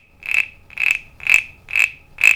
frog.wav